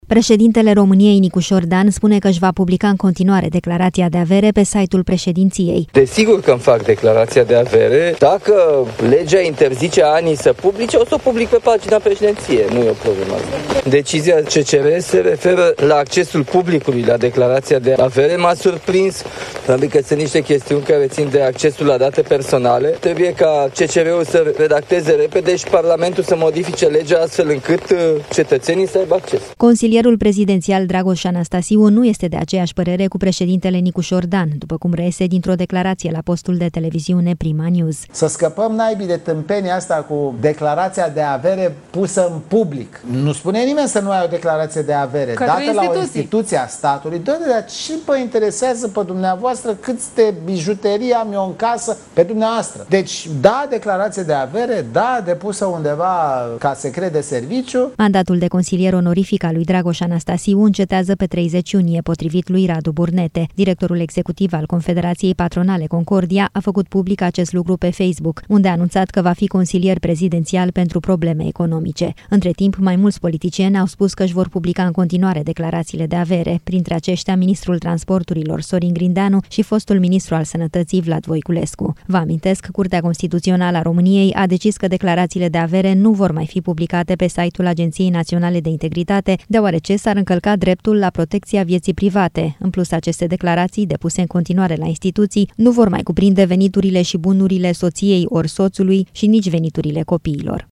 Consilierul prezidențial Dragoș Anastasiu nu este de aceeași părere cu președintele Nicușor Dan, după cum reiese dintr-o declarație la postul de televiziune PrimaNews.